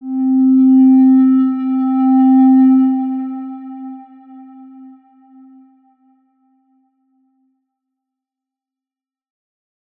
X_Windwistle-C3-ff.wav